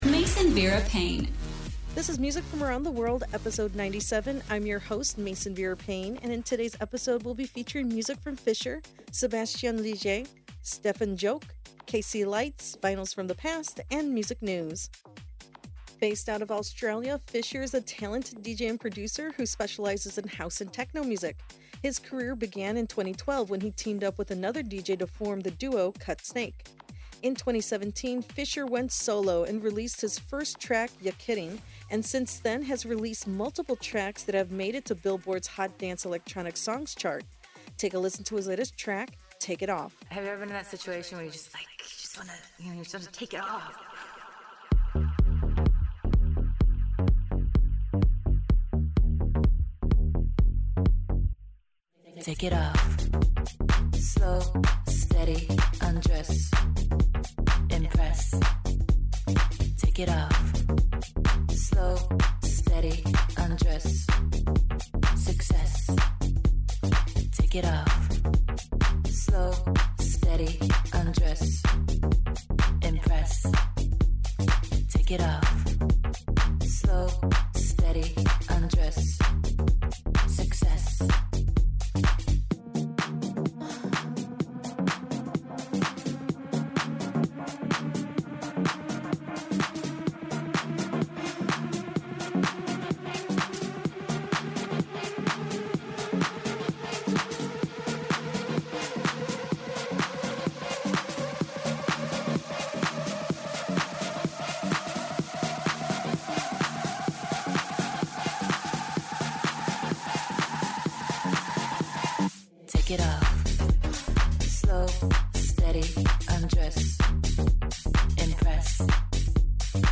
house and techno music